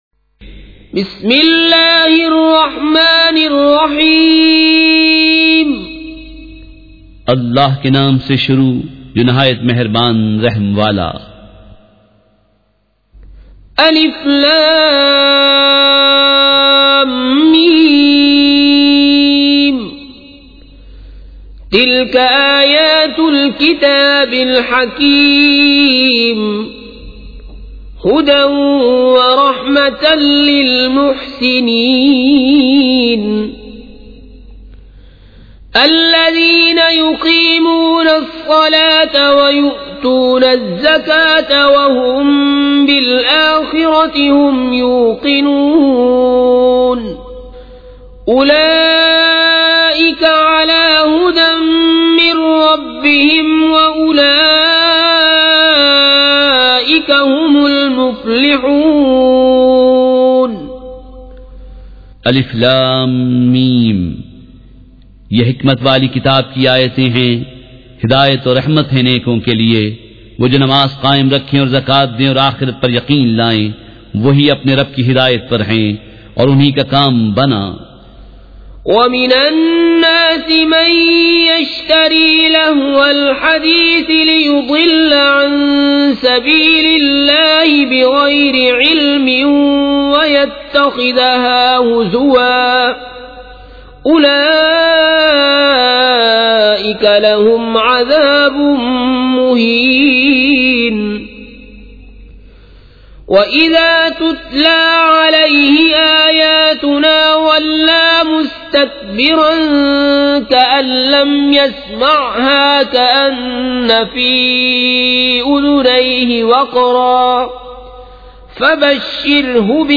سورۃ لقمٰن مع ترجمہ کنزالایمان ZiaeTaiba Audio میڈیا کی معلومات نام سورۃ لقمٰن مع ترجمہ کنزالایمان موضوع تلاوت آواز دیگر زبان عربی کل نتائج 1751 قسم آڈیو ڈاؤن لوڈ MP 3 ڈاؤن لوڈ MP 4 متعلقہ تجویزوآراء